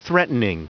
Prononciation du mot threatening en anglais (fichier audio)
Prononciation du mot : threatening